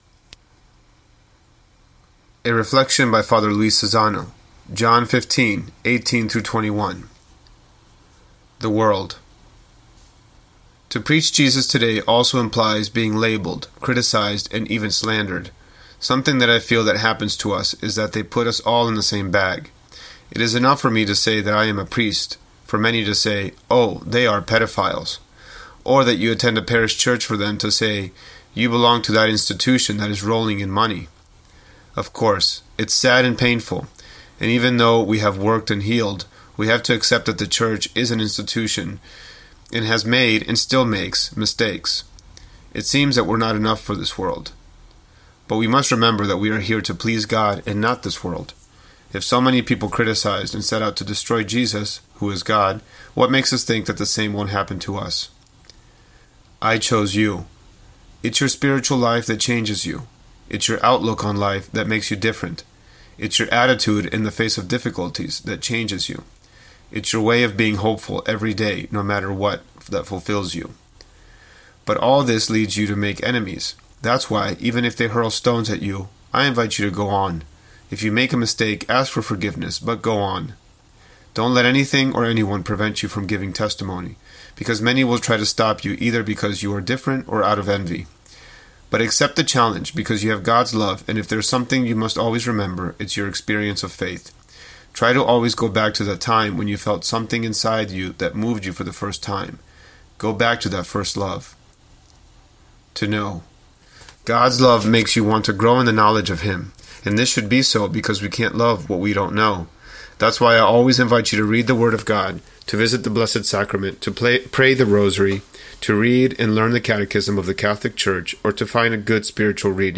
Daily Meditation